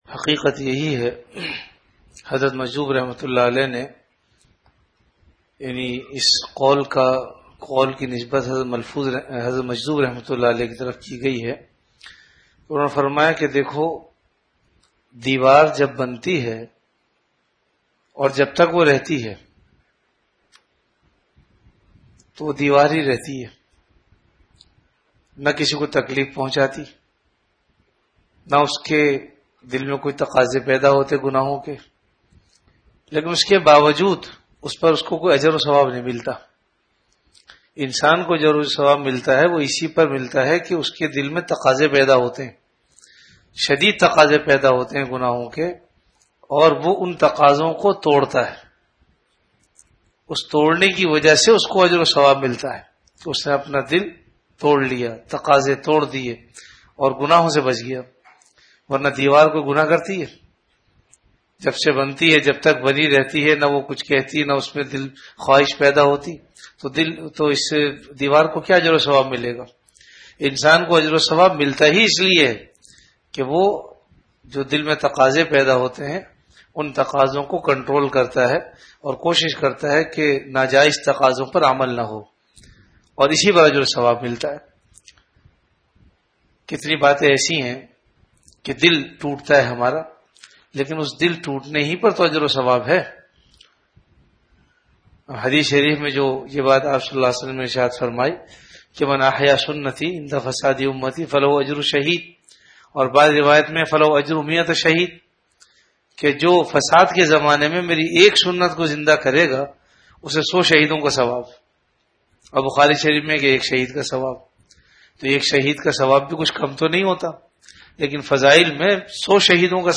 Majlis-e-Zikr · Jamia Masjid Bait-ul-Mukkaram, Karachi